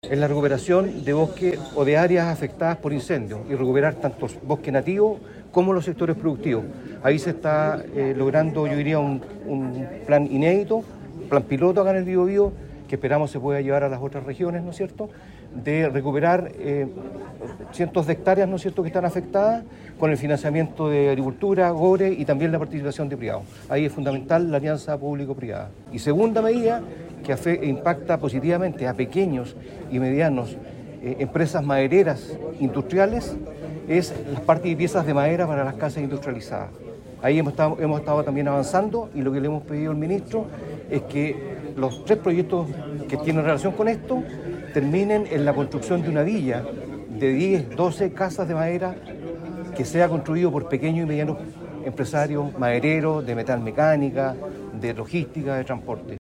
La cita tuvo lugar en el auditorio de la Cámara Chilena de la Construcción en Concepción y forma parte del despliegue integral del Gobierno para enfrentar el impacto económico tras el cierre de la Compañía Siderúrgica Huachipato.